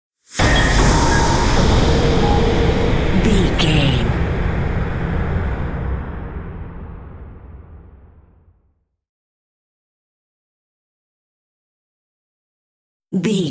Sound Effects
Aeolian/Minor
ominous
eerie
Horror Synths
Horror Synth Ambience